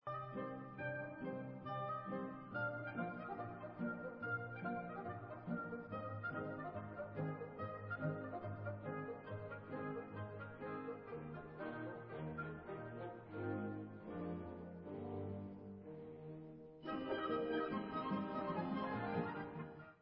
F dur (Tempo di menuetto) /Sousedská